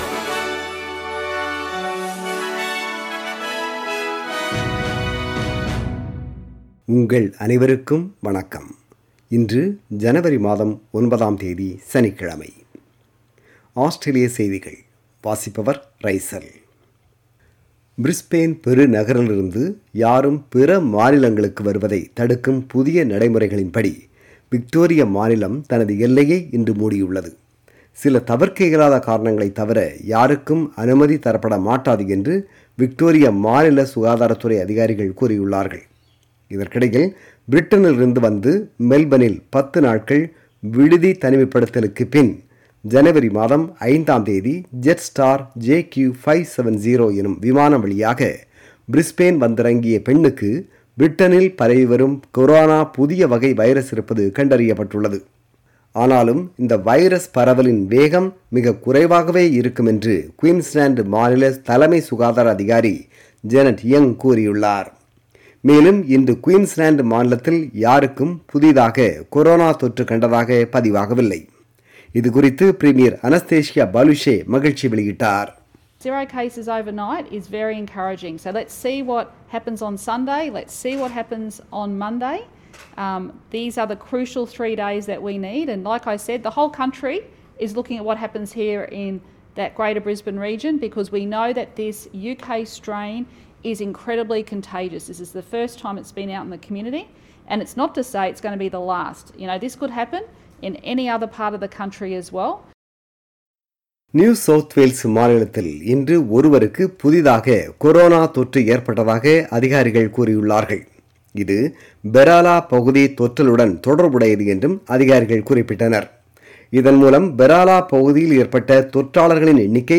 Australian News: 09 January 2021 – Saturday